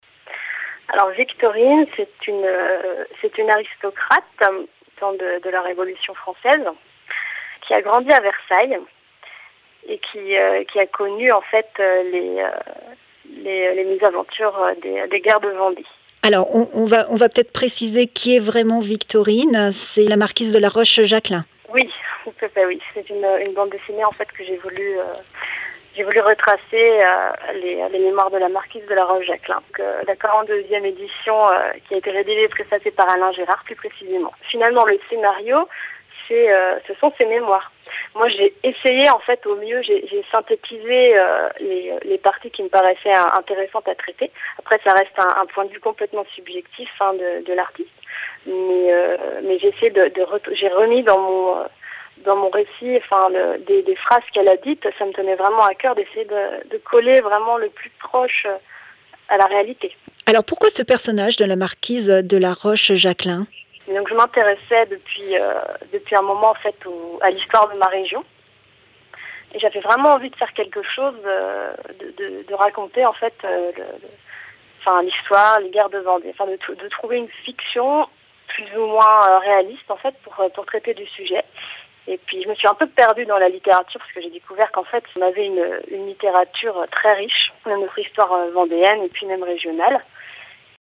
Emissions de la radio RCF Vendée
Catégorie Témoignage